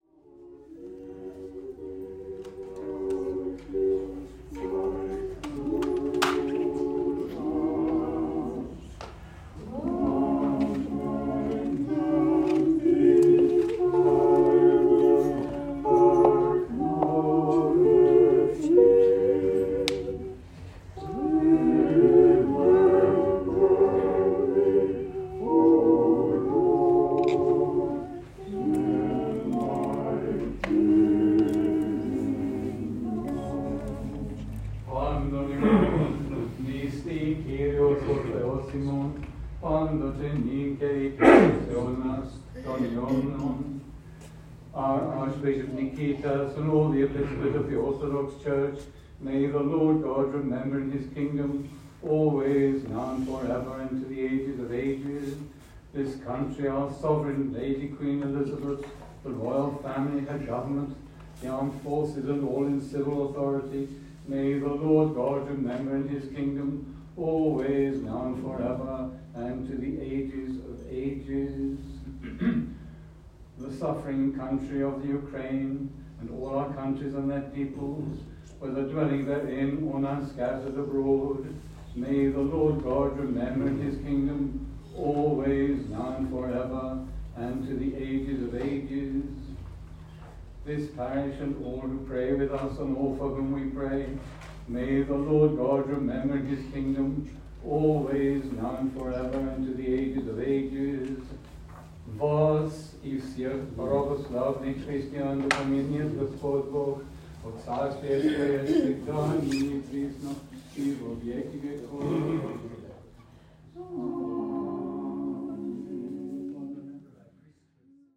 Μεγάλη Πέμπτη στην Αγγλία
Μια μικρή εκκλησία, για όλους τους Ορθόδοξους Χριστιανούς, ανεξάρτητα από την χώρα προέλευσης τους, όπως διαβάσαμε στην είσοδο, κάτι που έγινε αντιληπτό και κατά τη διάρκεια της Θείας Λειτουργίας, αφού εκτός από την αγγλική γλώσσα που επικρατούσε, ακούστηκαν ελληνικά, βουλγαρικά, ουκρανικά, ρωσικά.
Οι γλυκιές ψαλμωδίες που έφταναν στα αυτιά μας από τα μέλη της μικρής χορωδίας δημιουργούσαν μια όμορφη, κατανυκτική ατμόσφαιρα και μέσα στην Εβδομάδα των Παθών έστελναν ένα τόσο επίκαιρο μήνυμα για την ενότητα των λαών!